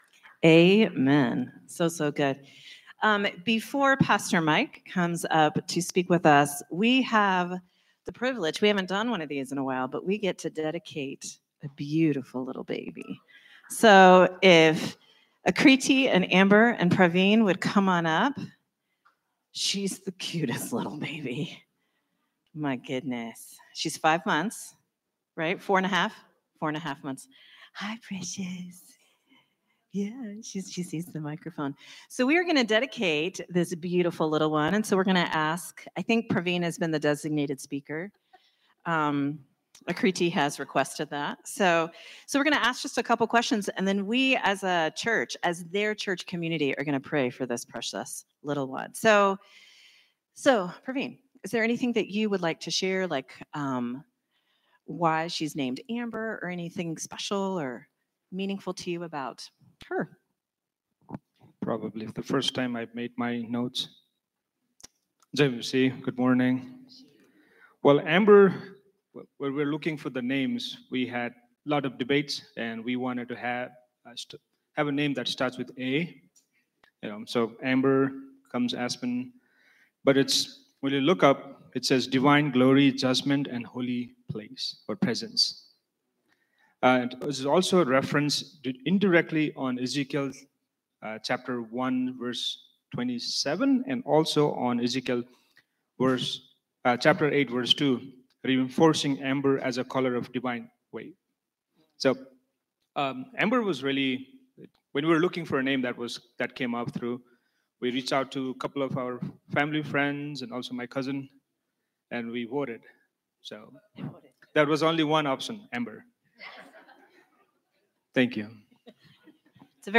Sermon from Celebration Community Church on August 3, 2025